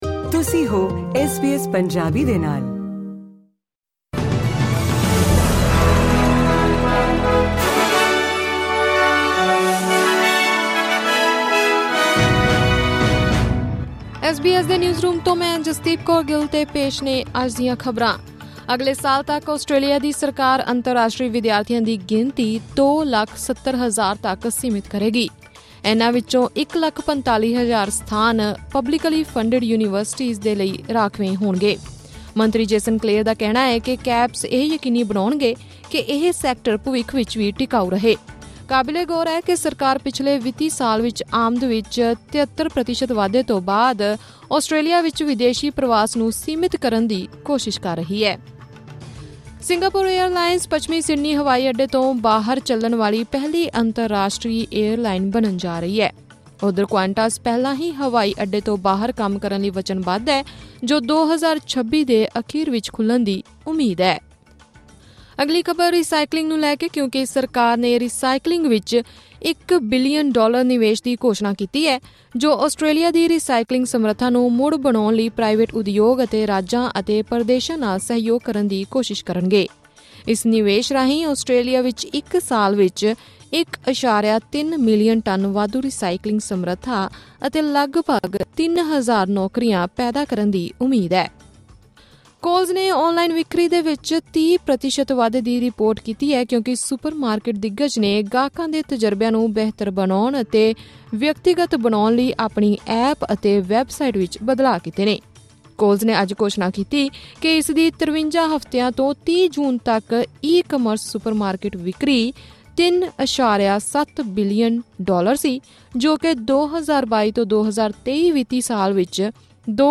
ਐਸ ਬੀ ਐਸ ਪੰਜਾਬੀ ਤੋਂ ਆਸਟ੍ਰੇਲੀਆ ਦੀਆਂ ਮੁੱਖ ਖ਼ਬਰਾਂ: 27 ਅਗਸਤ 2024